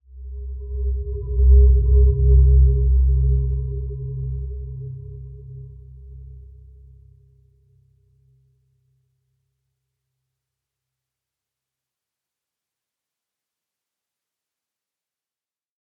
Dreamy-Fifths-C2-p.wav